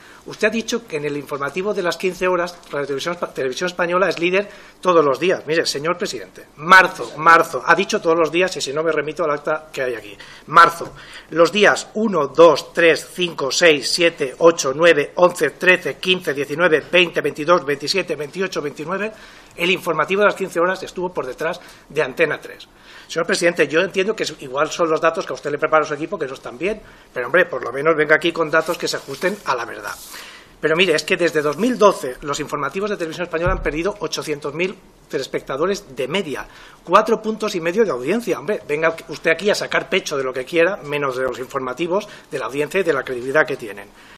Fragmento de la intervención de Germán Rodríguez en la Comisión de Control de RTVE 28/04/2015